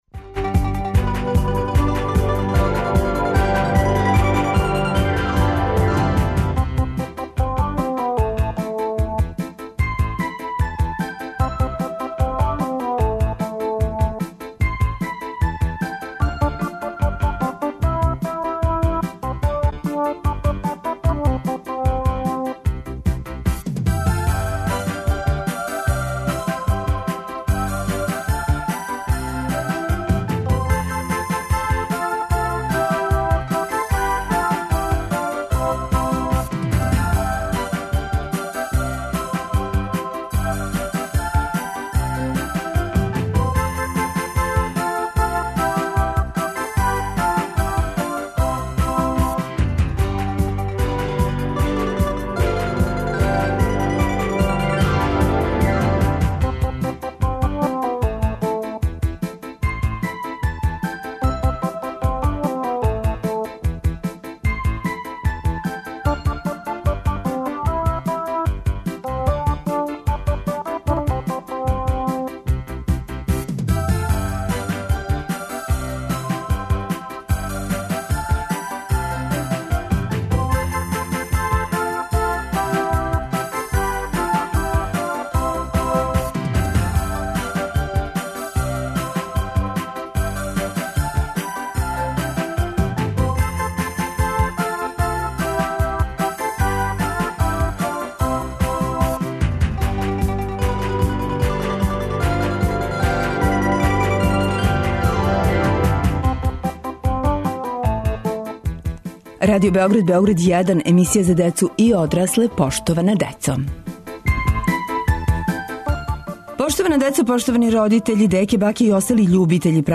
Шта је услов да се добију поклони од Деда Мраза, када и како их он доноси и да ли је могуће да сваки пут испуни жеље? Ово су само нека од питања на која ћемо одговорити уз помоћ наших гостију, малишана, који полако одбројавају дане до доласка Деда Мраза.